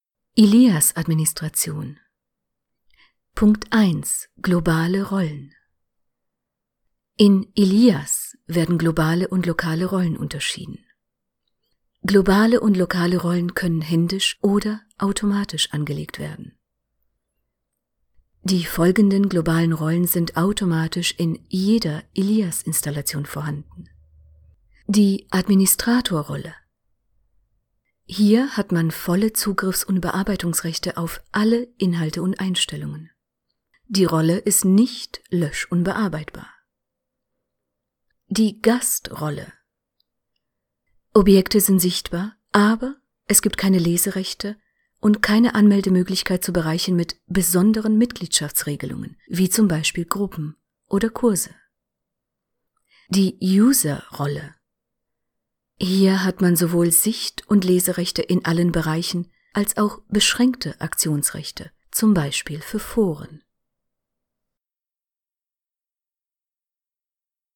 Du bist auf der Suche nach einer Sprecherin, die:
E-Learning Texte, die nicht langweilig sein müssen.